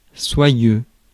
Ääntäminen
Synonyymit doux Ääntäminen France: IPA: /swa.jø/ Haettu sana löytyi näillä lähdekielillä: ranska Käännös 1. sedoso Suku: m .